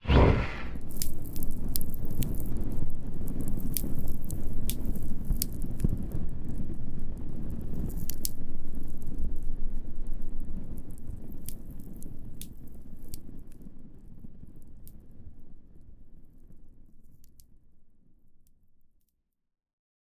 create-bonfire-002.ogg